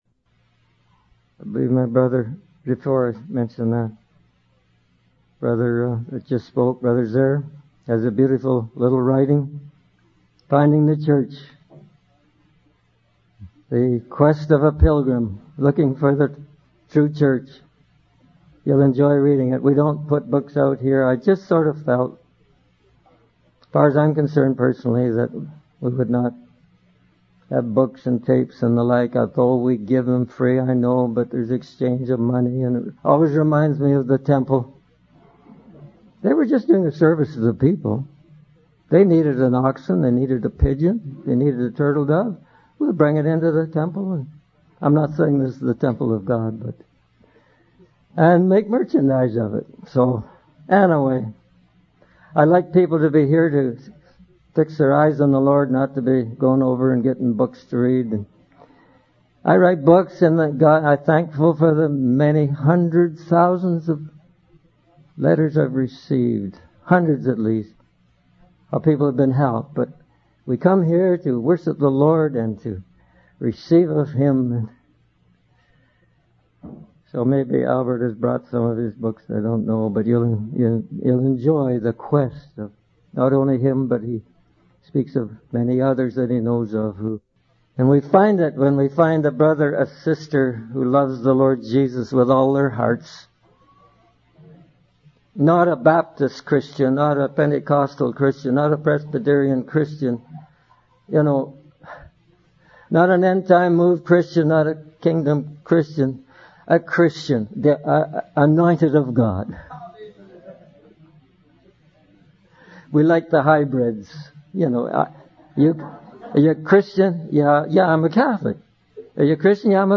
In this sermon, the speaker shares his personal testimony of how God called his family to become missionaries in Colombia. He emphasizes that following God's call may require sacrifice and testing, but it is necessary for the ministry of the gospel to advance. The speaker then references the story of Elijah on Mount Carmel, where the fire of God fell and turned the hearts of the people back to Him.